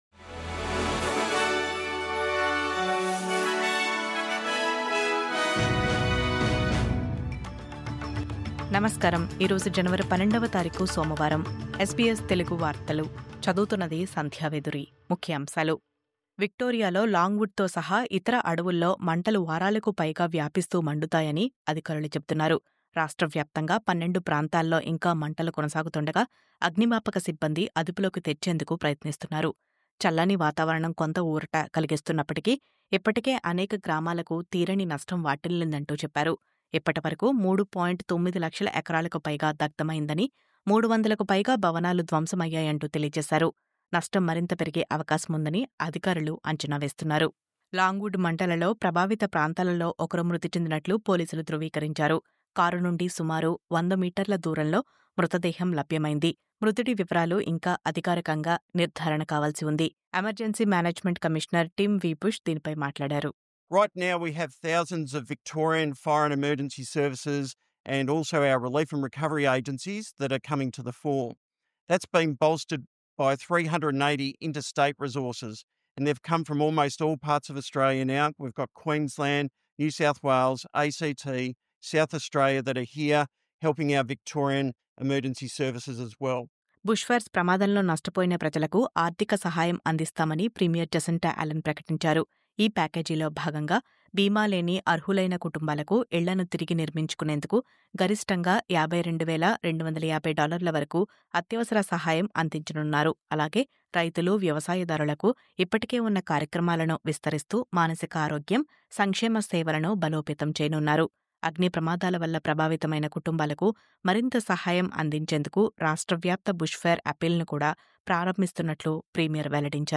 News update: తీవ్రవాదాన్ని ప్రేరేపించే కేంద్రాలపై ప్రభుత్వం ఉక్కు పాదం.. కౌన్సిళ్లకు రద్దు చేసే అధికారాలు..